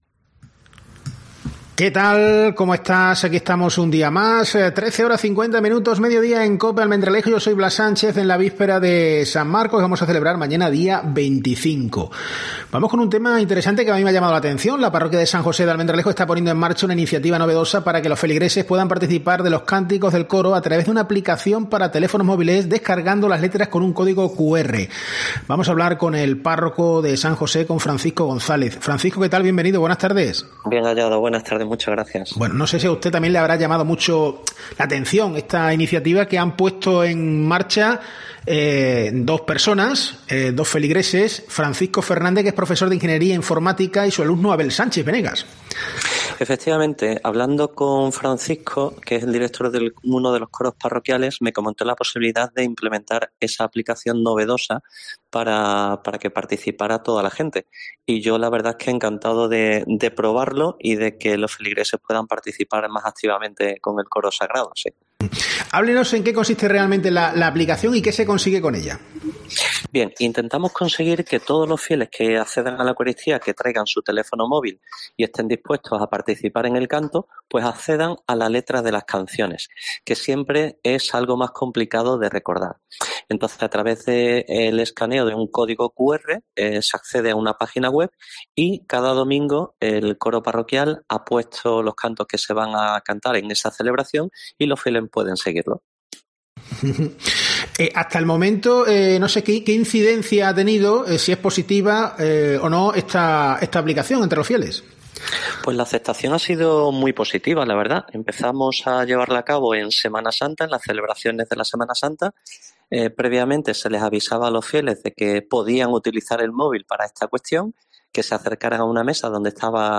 Con él hemos hablado en COPE.